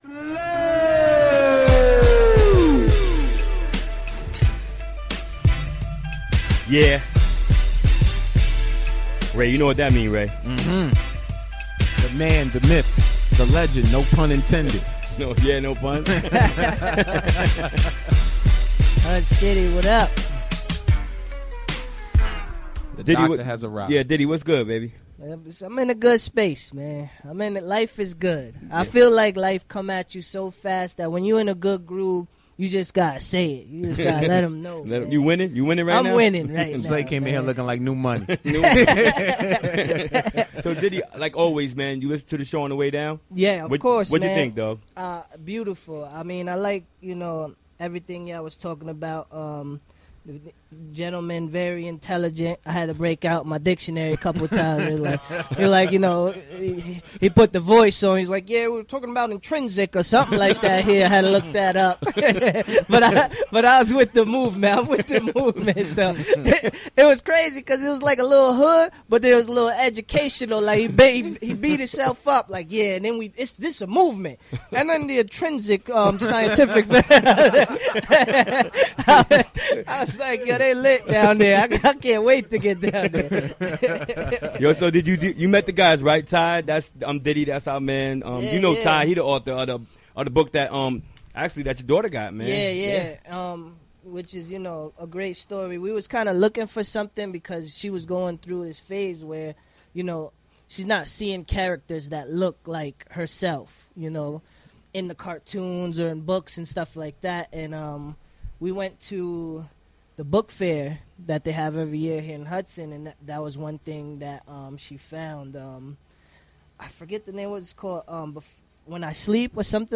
Interview was conducted during the WGXC Afternoon Show.